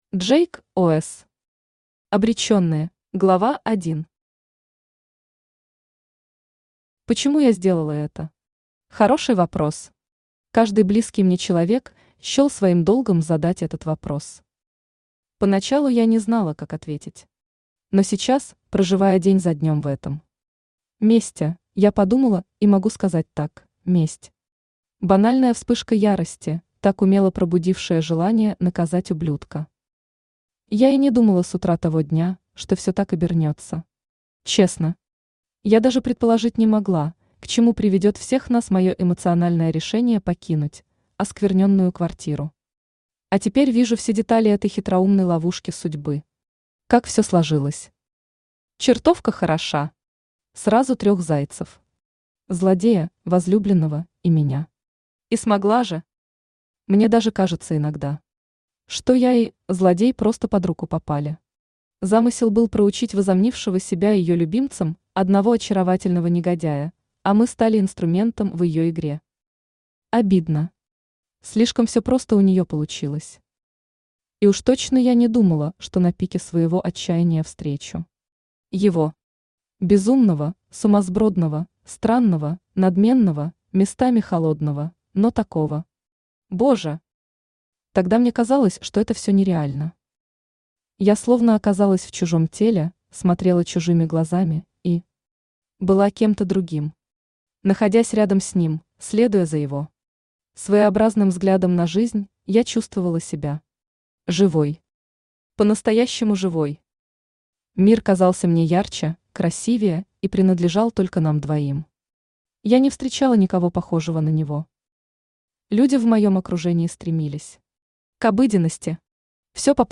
Аудиокнига Обречённые | Библиотека аудиокниг
Aудиокнига Обречённые Автор Джейк Ос Читает аудиокнигу Авточтец ЛитРес.